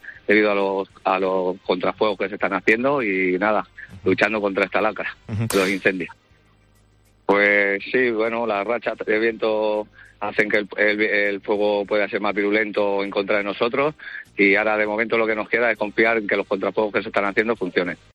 El alcalde de Pedro Bernanrdo, David Segovia ha explicado en Herrera en COPE que los trabajos ahora se centran en crear contrafuegos para evitar daños mayores.